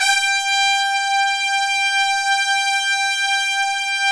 Index of /90_sSampleCDs/Roland LCDP06 Brass Sections/BRS_Tpts mp)f/BRS_Tps Velo-Xfd